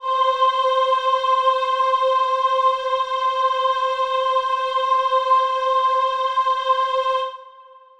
Choir Piano (Wav)
C5.wav